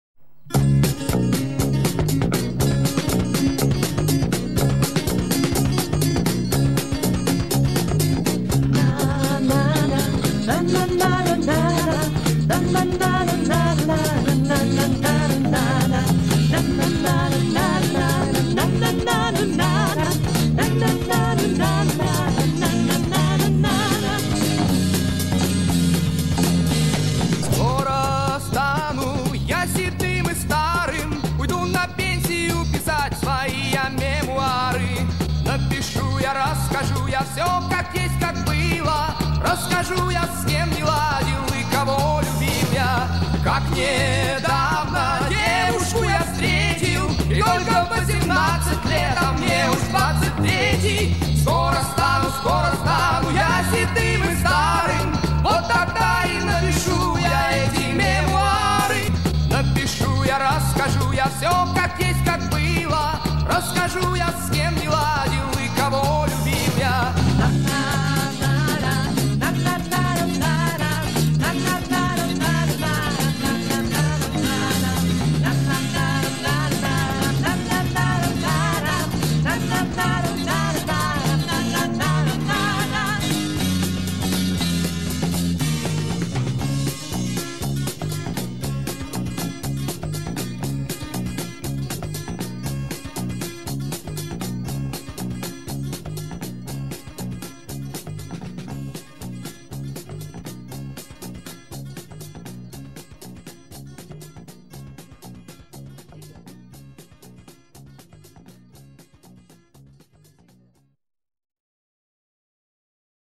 без звуков из фильма